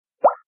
DRIP
DRIP - Tono movil - EFECTOS DE SONIDO
Tonos gratis para tu telefono – NUEVOS EFECTOS DE SONIDO DE AMBIENTE de DRIP
drip.mp3